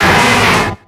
Cri de Lokhlass dans Pokémon X et Y.